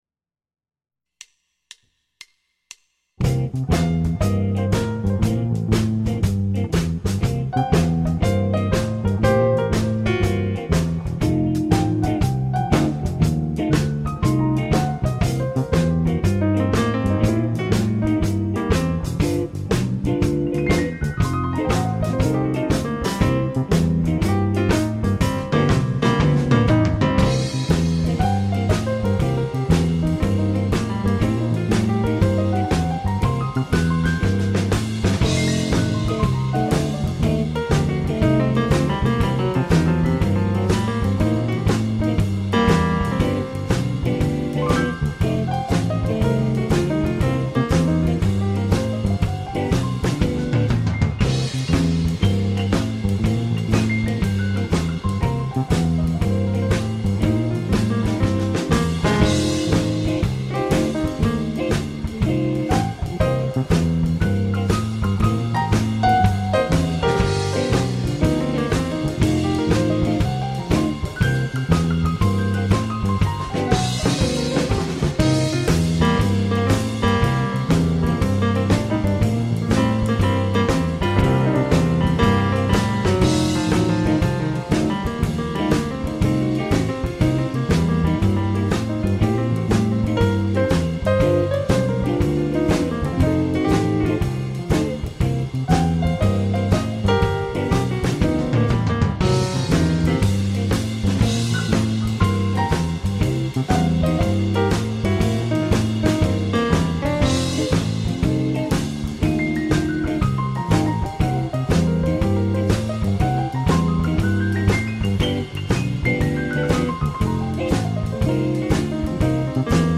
אני מבין שאתה על הפסנתר, אבל מי על התופים והבס?